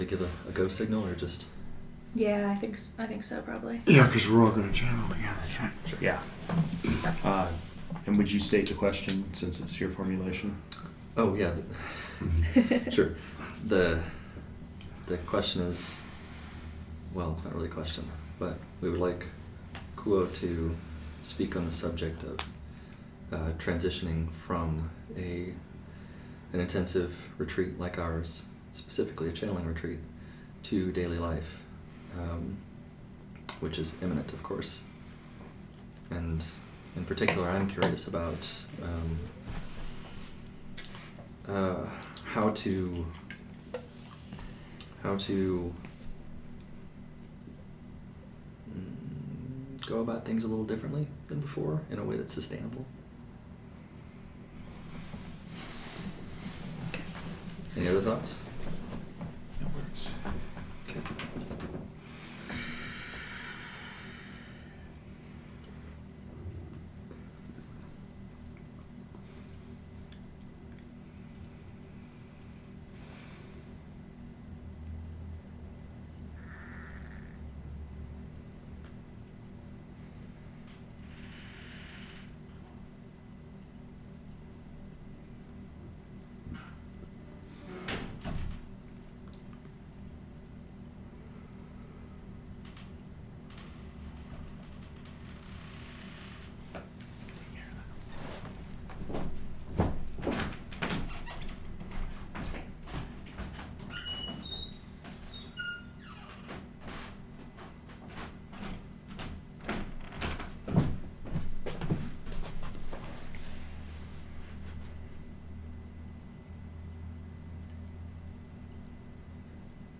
In this final session from the Other Selves Working Group’s first channeling intensive, Q’uo counsels the channeling circle on their commitment to ongoing, remote spiritual work upon returning home. They urge the group to discover a balance between the daily duties of life and the responsibility of serving as channels, commenting on the importance of accountability and clear communication.